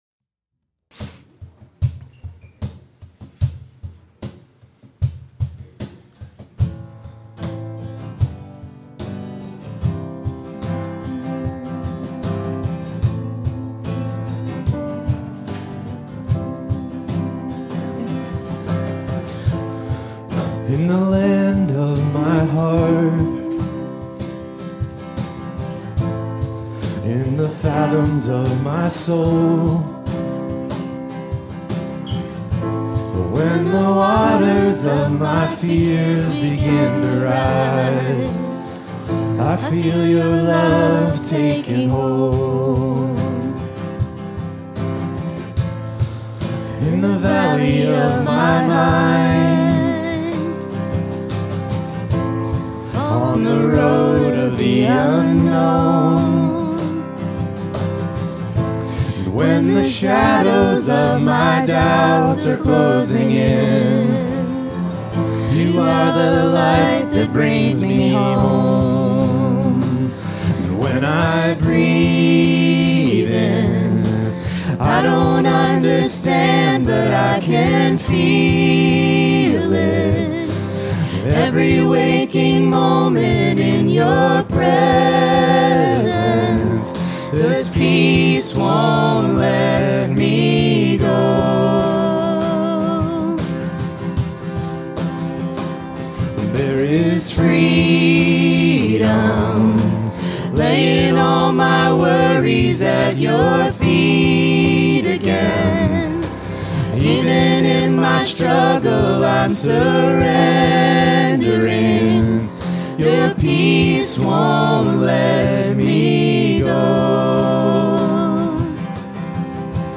Worship 2025-05-18